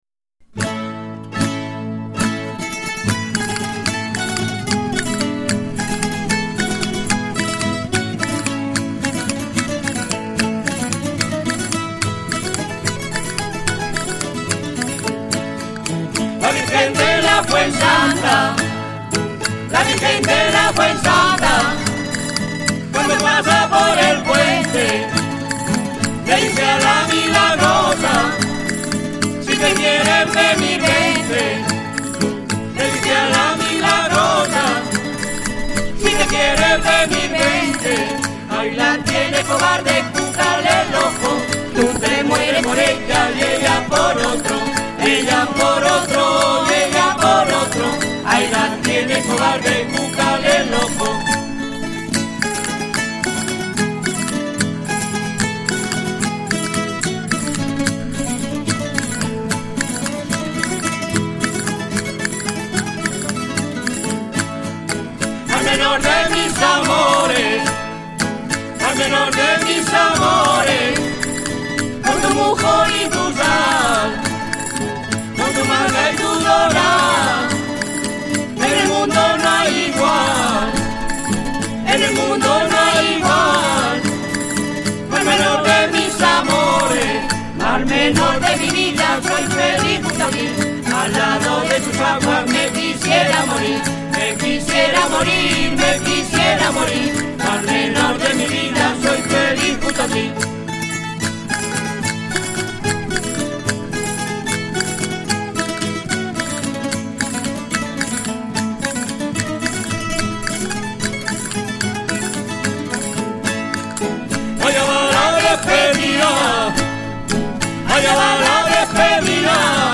Rondalla Santiago Apostol
12_Jota_de_Aguilas.mp3